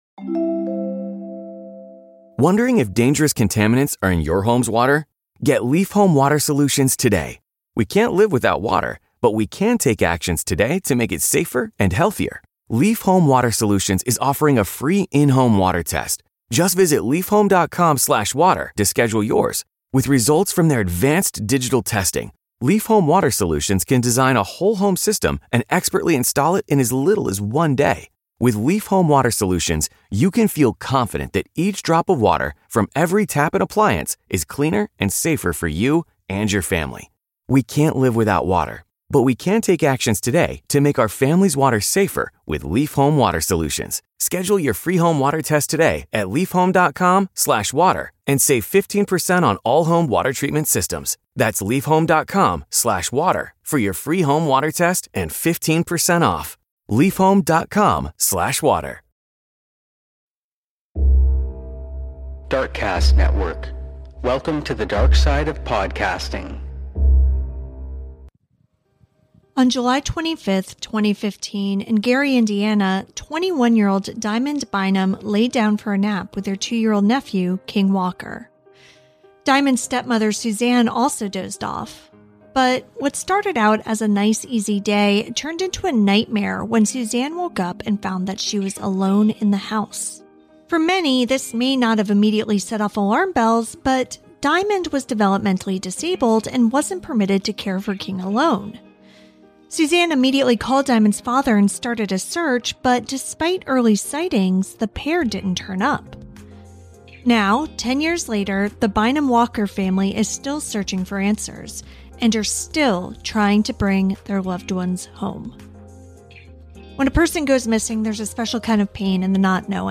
On Our Watch Season 2: New Folsom is a deeply-reported narrative podcast series from KQED. It follows the stories of two correctional officers in an elite unit who pay a high price for exposing corruption and abuse by their fellow officers.